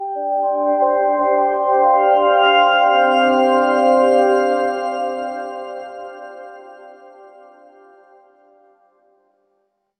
🎵 Background Music